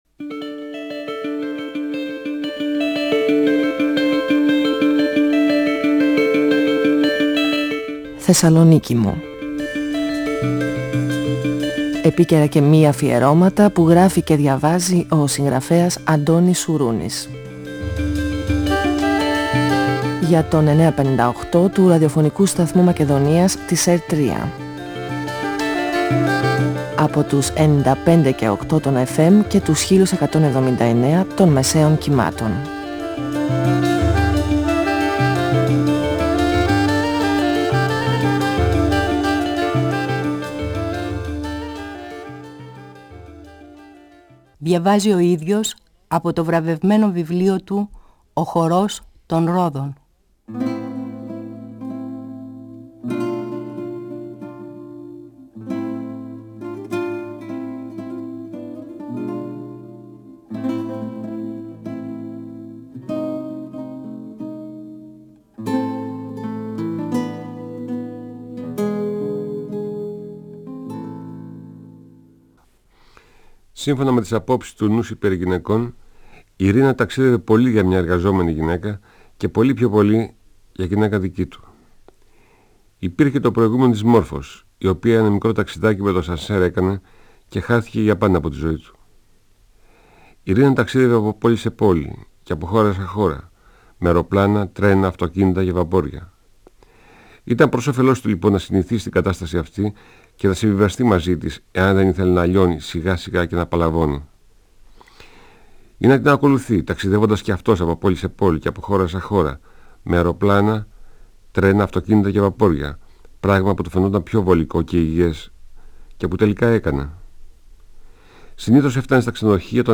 Ο συγγραφέας Αντώνης Σουρούνης (1942-2016) διαβάζει από το βιβλίο του «Ο χορός των ρόδων», εκδ. Καστανιώτη, 1994. Ο Νούσης φοβάται για τη σχέση του με την Ιρίνα και αποφασίζει να την ακολουθεί στα ταξίδια της και στις επιδείξεις μόδας.